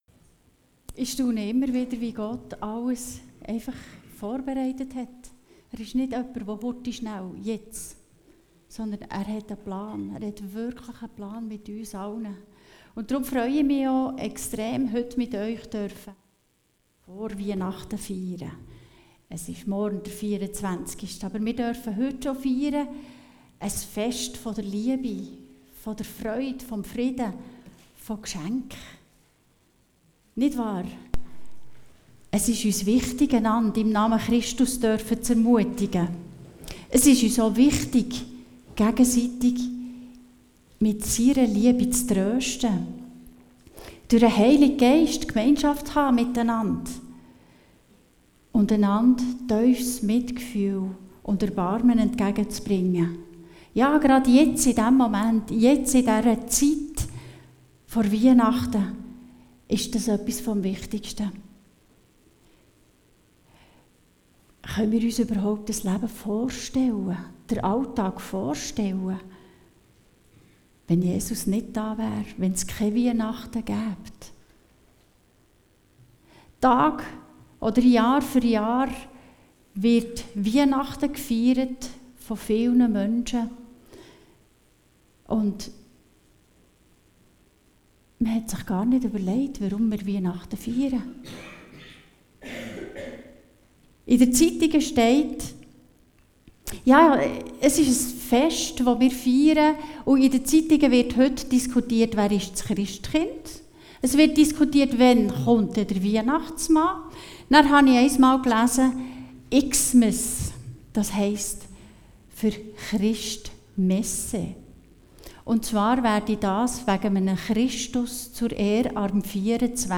Phil. 2,6-9 Dienstart: Gottesdienst Dateien zum Herunterladen Notizen « Ganz anders!